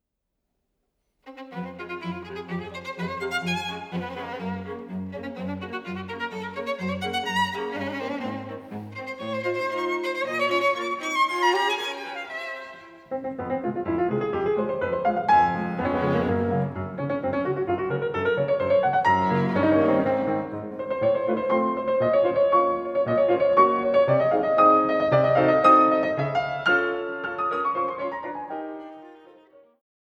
Violine
Viola
Violoncello